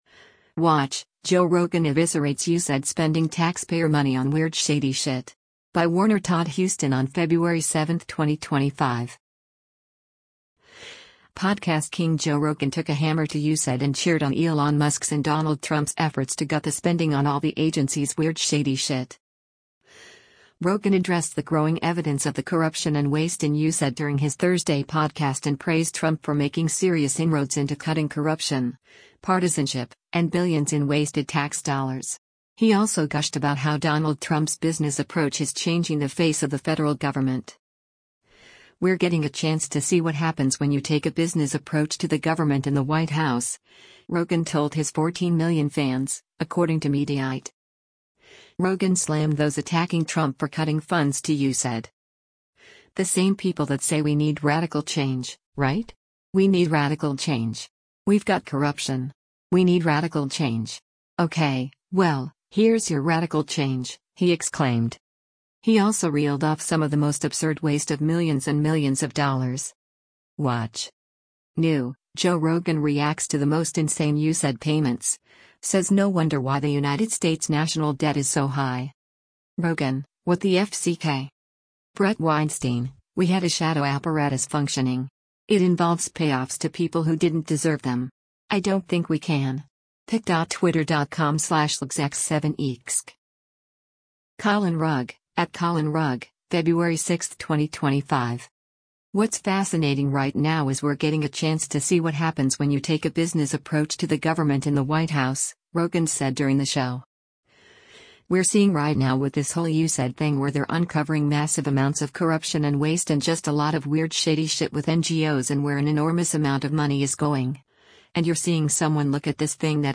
Rogan addressed the growing evidence of the corruption and waste in USAID during his Thursday podcast and praised Trump for making serious inroads into cutting corruption, partisanship, and billions in wasted tax dollars.